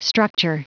Prononciation du mot structure en anglais (fichier audio)
Prononciation du mot : structure